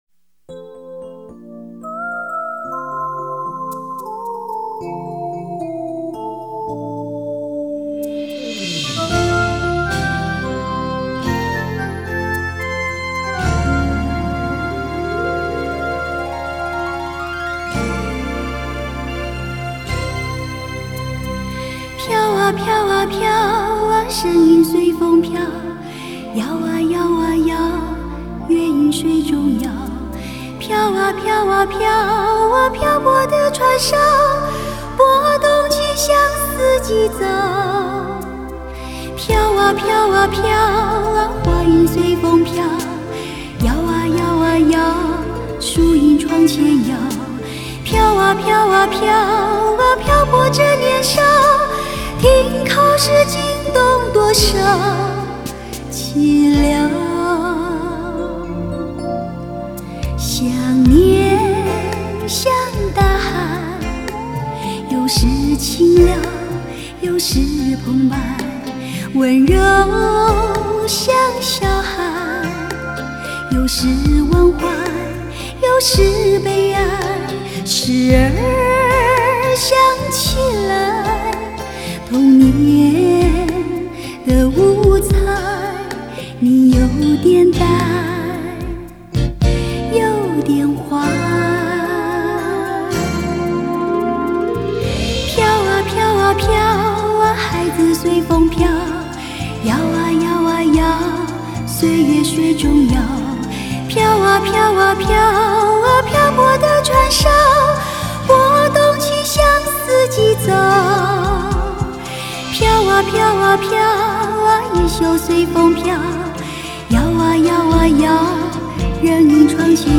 44.100 Hz;16 Bit;立体声